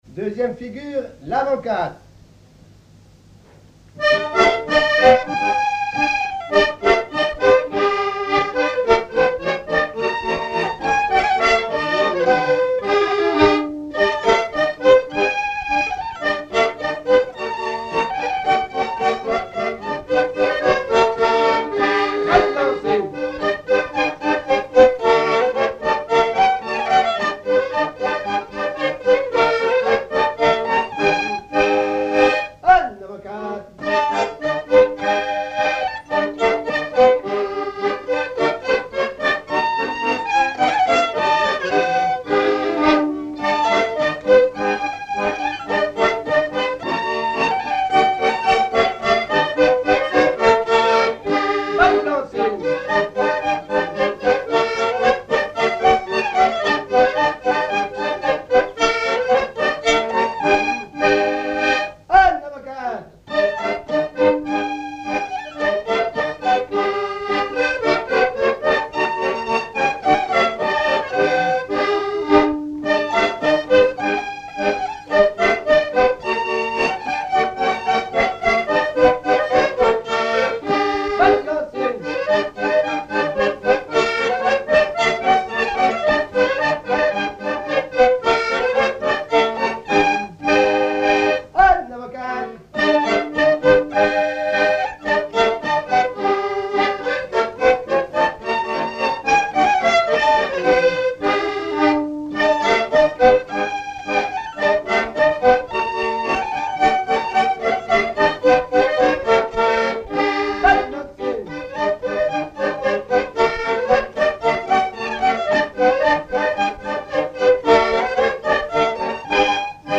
Rochetrejoux
danse : quadrille : avant-quatre
Pièce musicale inédite